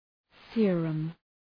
{‘sıərəm}